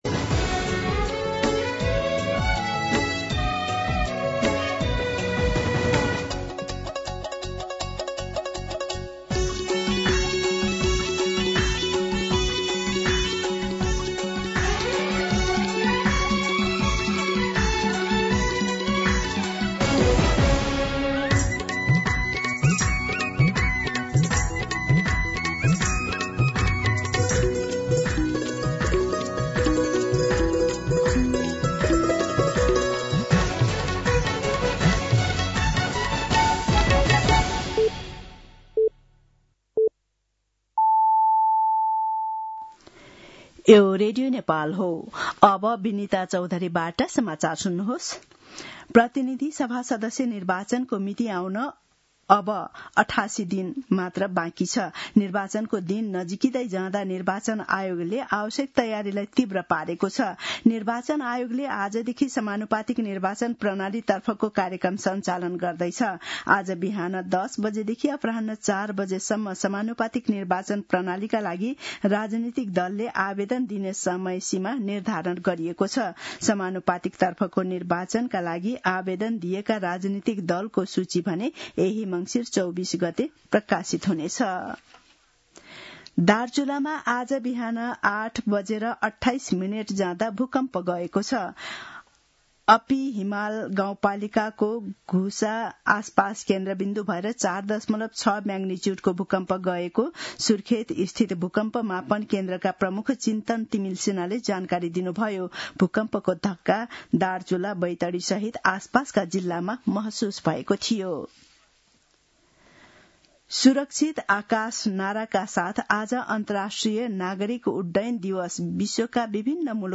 मध्यान्ह १२ बजेको नेपाली समाचार : २१ मंसिर , २०८२